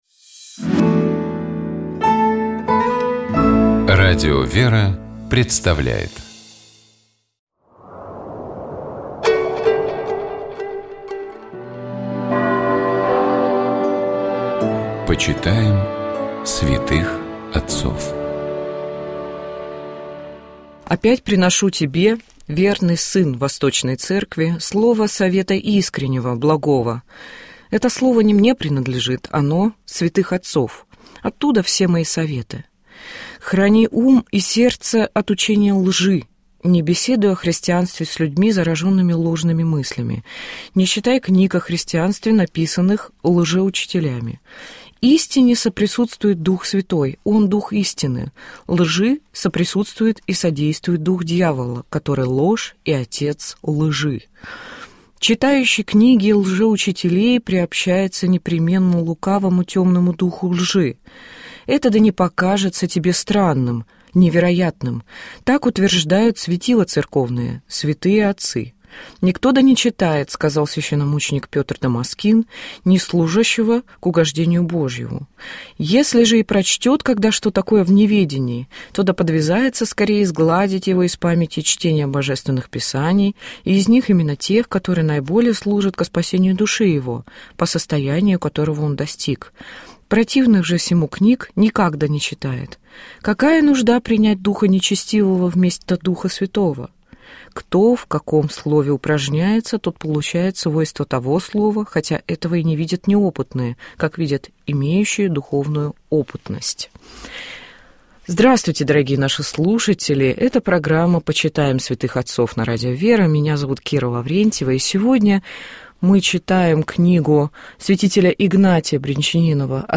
В беседе она рассказала о своём творческом пути, воспитании детей через искусство и важности преемственности традиций.